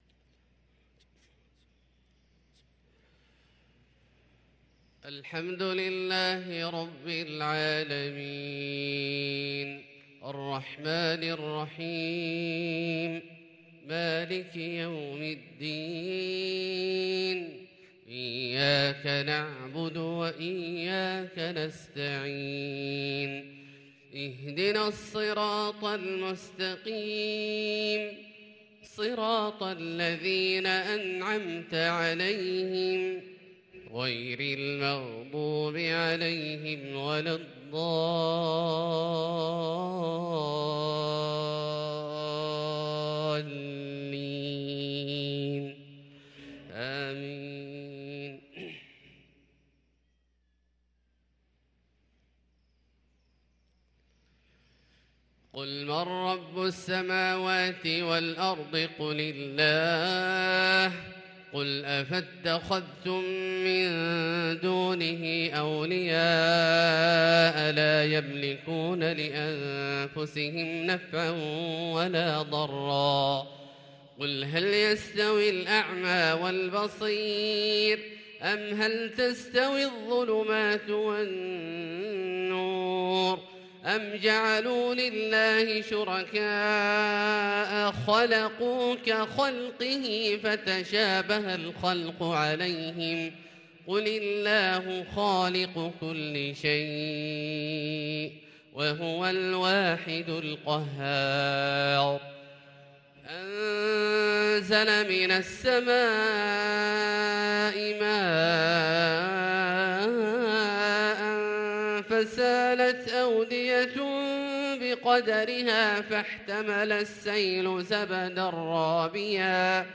صلاة الفجر للقارئ عبدالله الجهني 4 صفر 1444 هـ